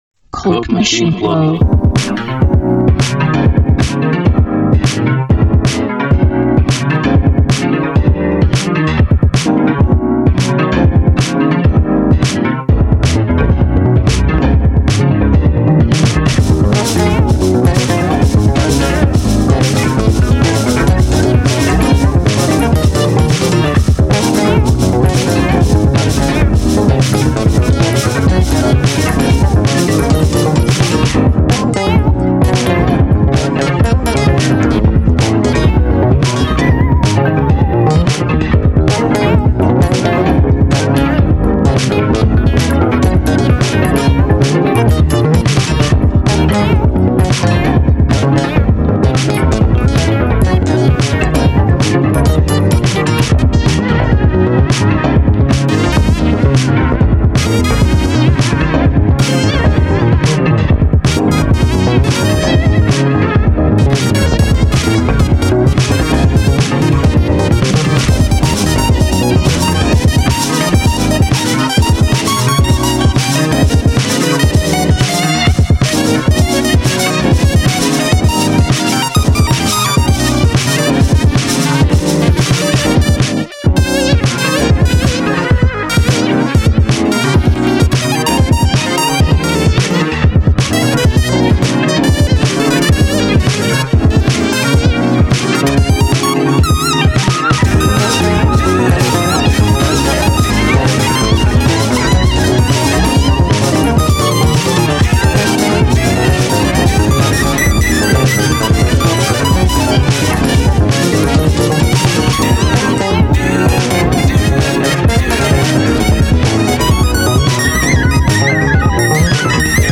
end-of-summer mix